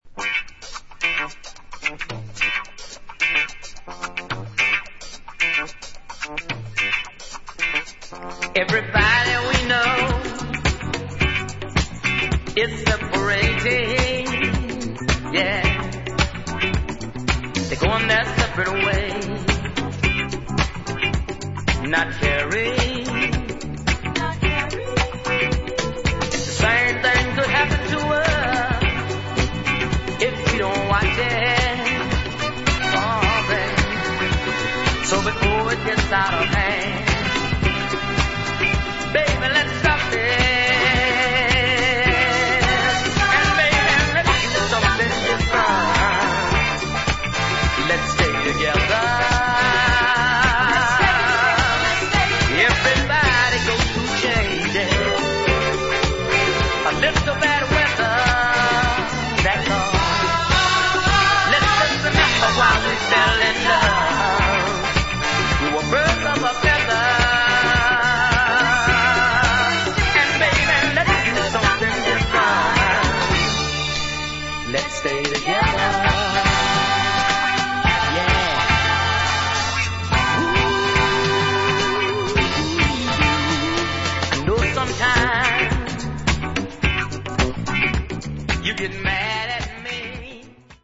Modern Soul spin